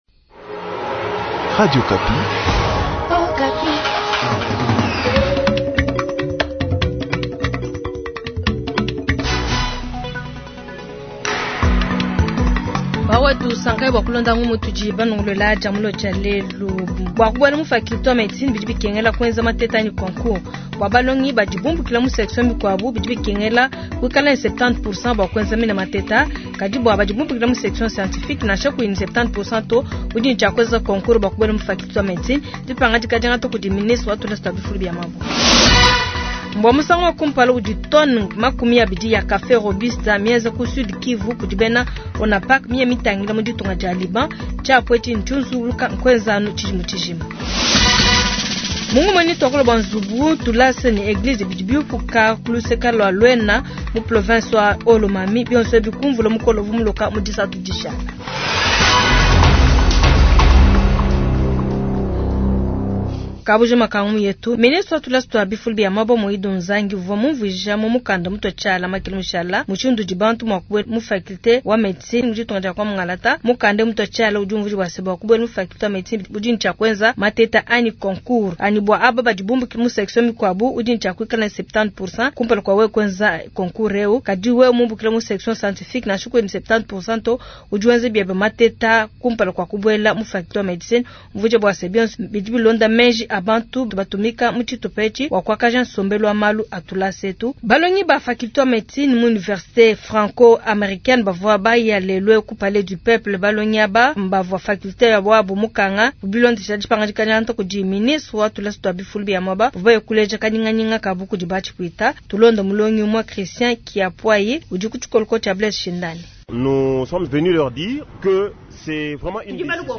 Journal Tshiluba du Jeudi 281021